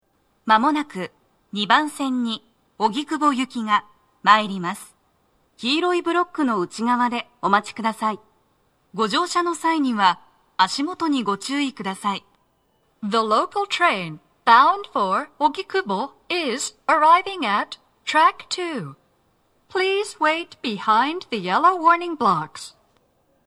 鳴動は、やや遅めです。
女声
接近放送1